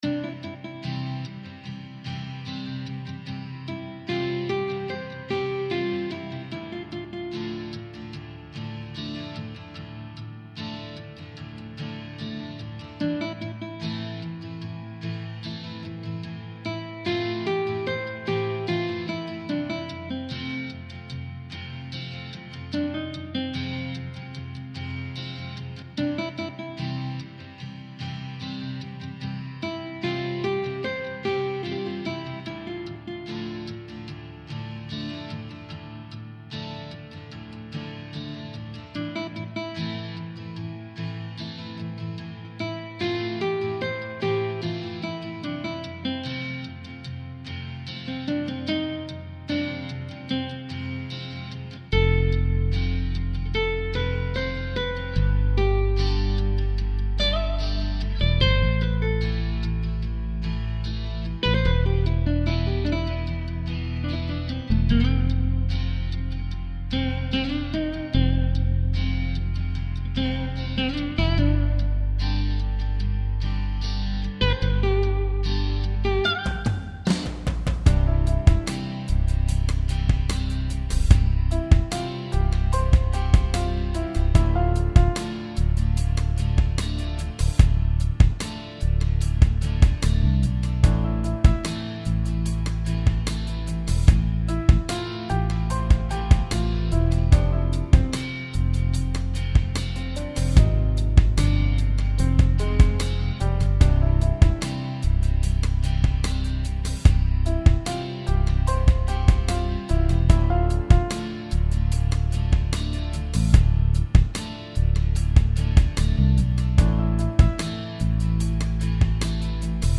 tristesse - guitare classique